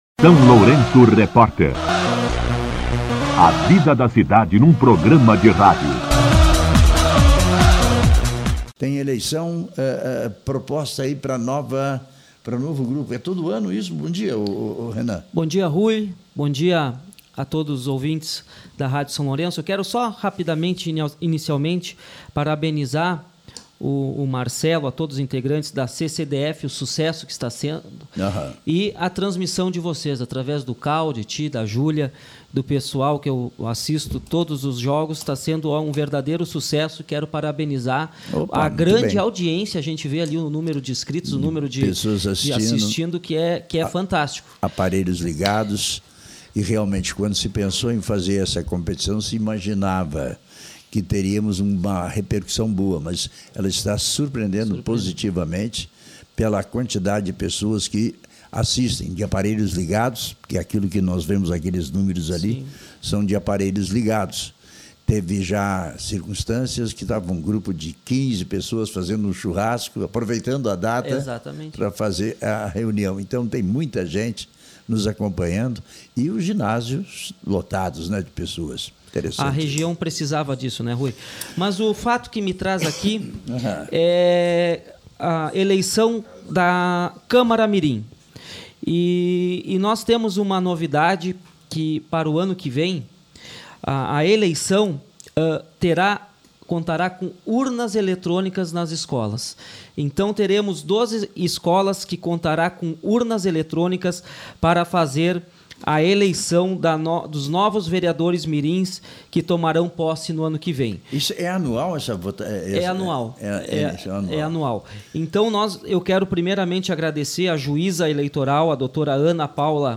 Esteve hoje no SLR Rádio o vereador Renan Hartwig (Progressistas) para falar sobre a eleição da Câmara Mirim, que acontecerá no dia 27 de novembro, das 9h às 16h, com a utilização de urnas eletrônicas nas escolas do município.
Entrevista com o vereador Renan Hartwig